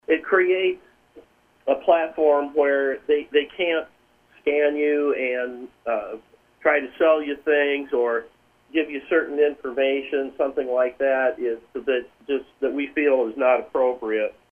State Representative Tom Jeneary of Le Mars says the bill places more restrictions on social media access to teens.